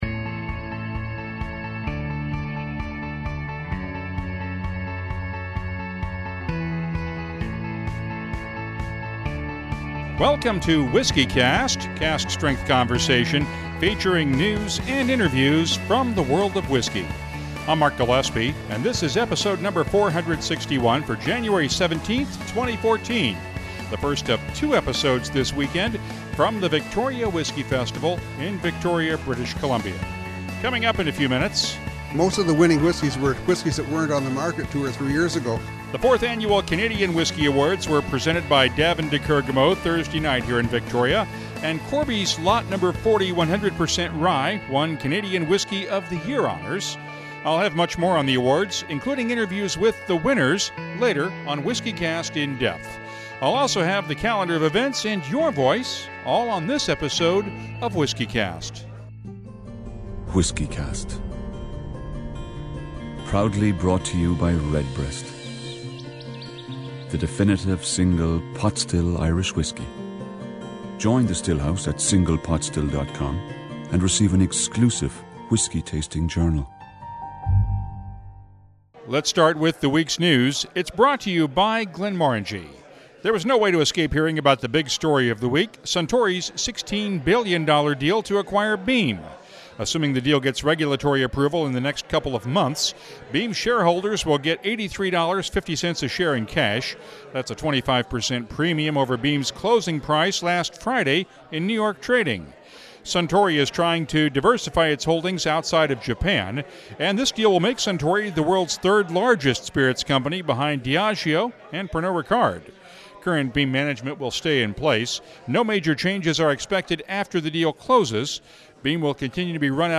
This is the first of two episodes from this weekend’s Victoria Whisky Festival in British Columbia, where Canadian whisky was featured on opening night with the presentation of the 2013 Canadian Whisky Awards. Corby’s Lot No. 40 ended Forty Creek Distillery’s three-year run as Canadian Whisky of the Year, but by the slimmest of margins. We’ll hear from the winners, as well as the equally pleased runners-up.